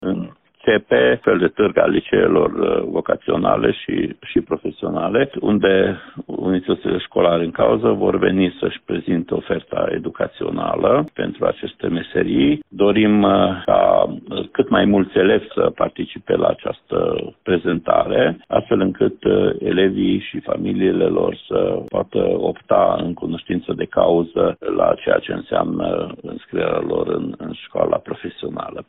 Revine Ștefan Someșan.